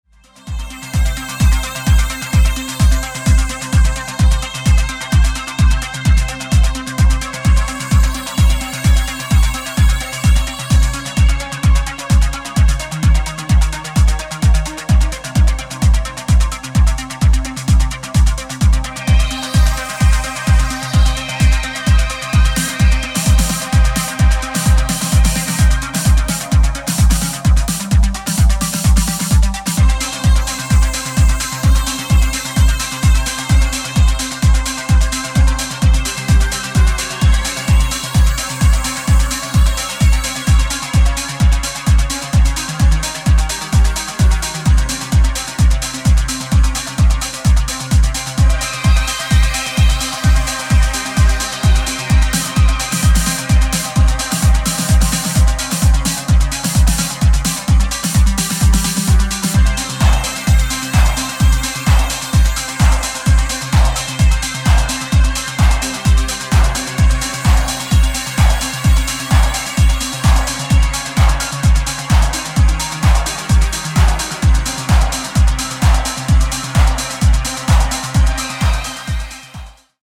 Exploring a dark fractal sound!